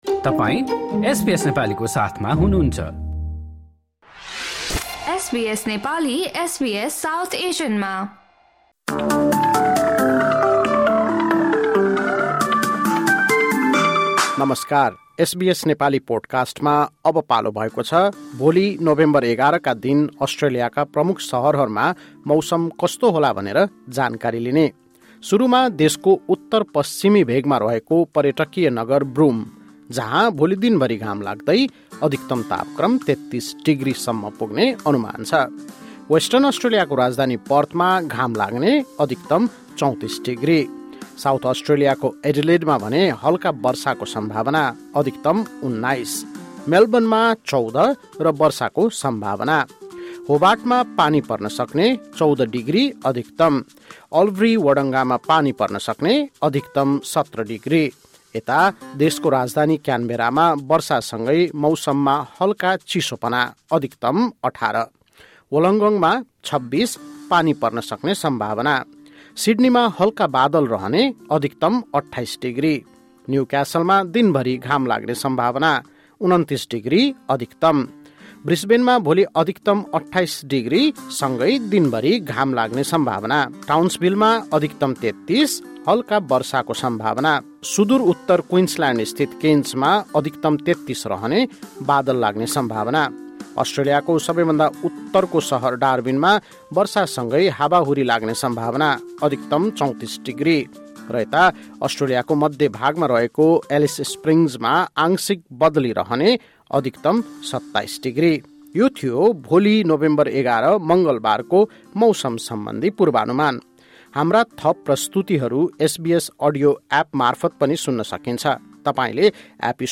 मङ्गलवार , ११ नोभेम्बरको अस्ट्रेलियन मौसम अपडेट नेपाली भाषामा सुन्नुहोस्